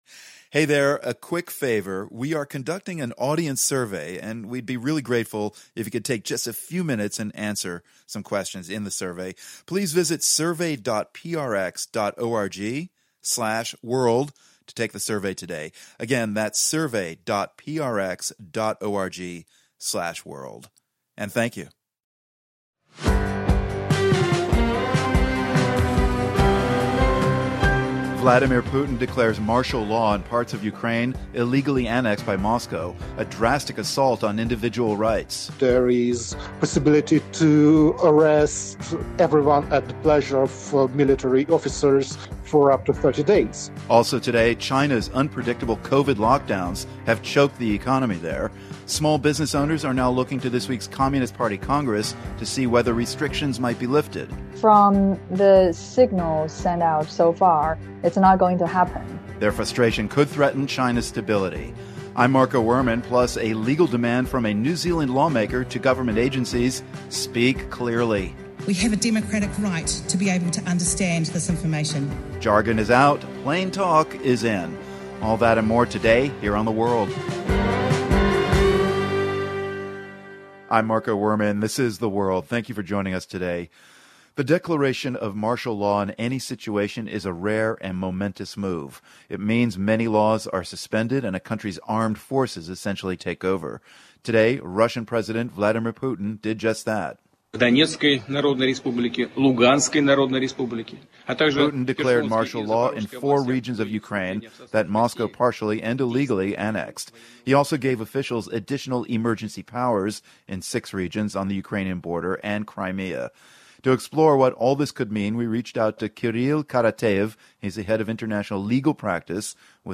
We hear from three women protesters from different generations in Tehran. Plus, a “plain language” bill passes in New Zealand.